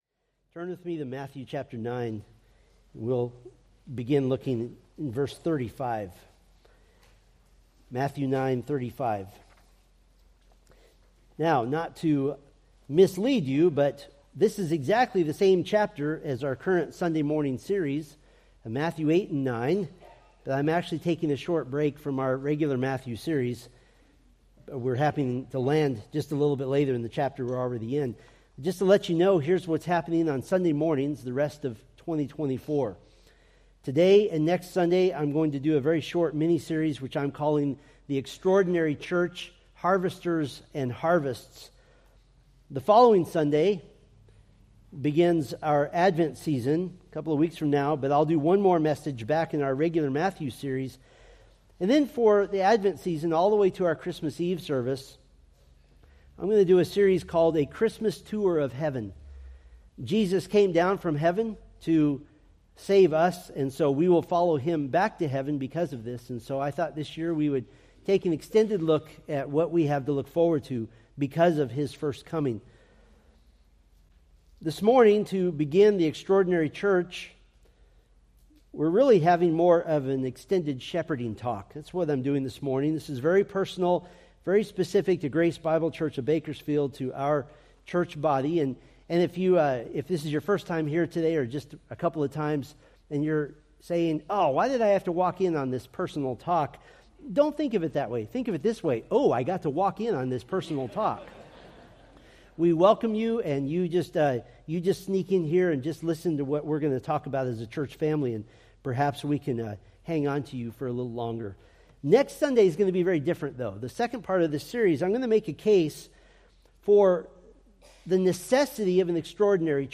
Preached November 17, 2024 from Selected Scriptures